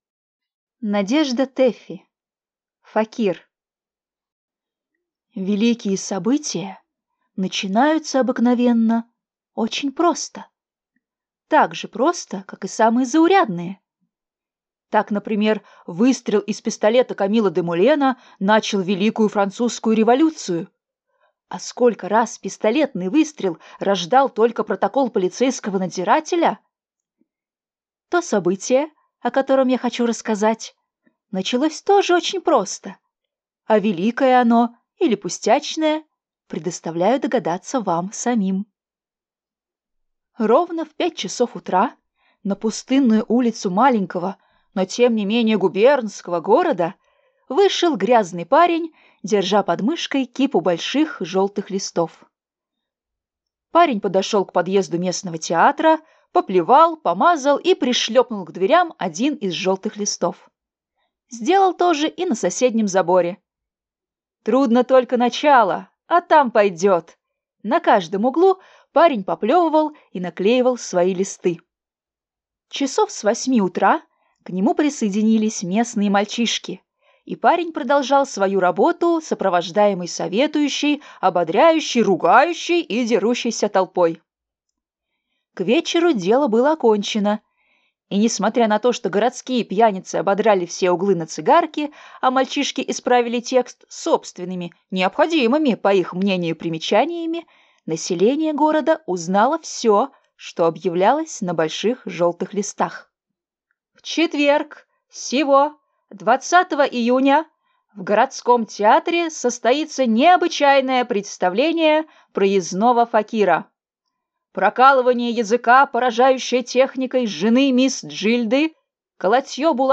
Аудиокнига Факир | Библиотека аудиокниг